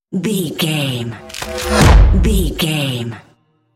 Dramatic whoosh to hit trailer
Sound Effects
Atonal
dark
futuristic
intense
tension